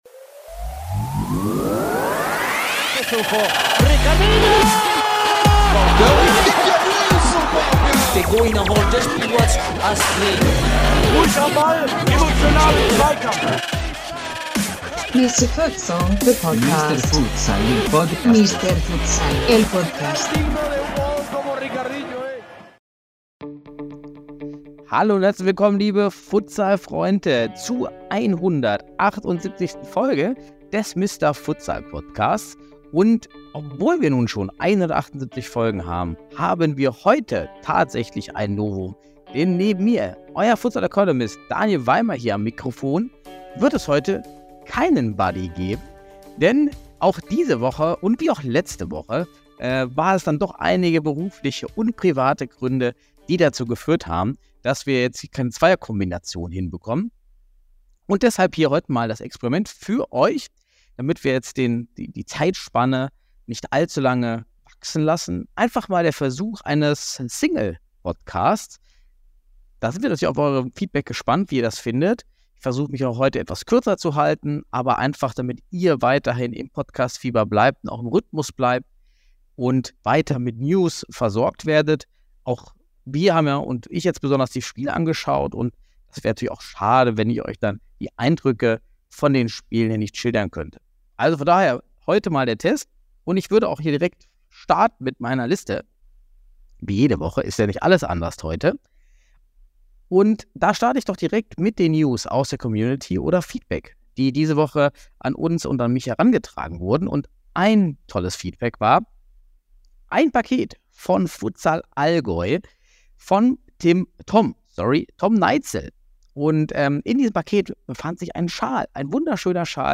Wir hoffen, dass die Solo-Folge eine gute Alternative zu einem fehlenden Podcast ist.